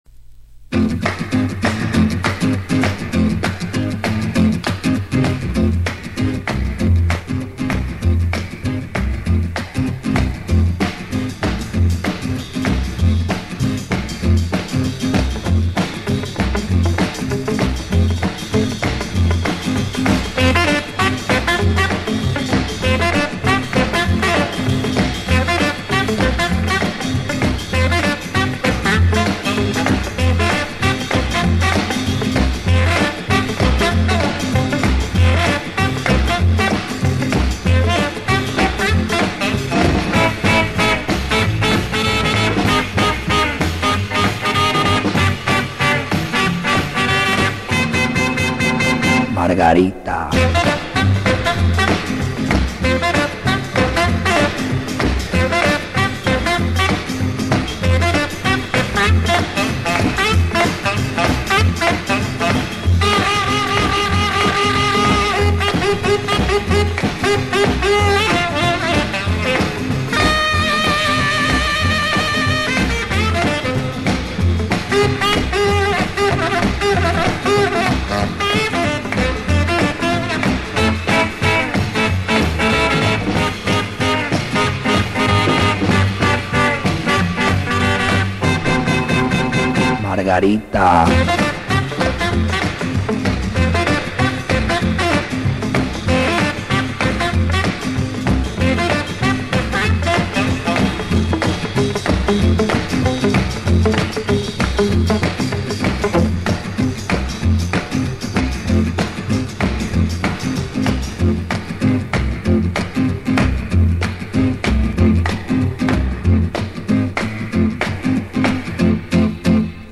Вторая запись (с рефреном) - это именно она.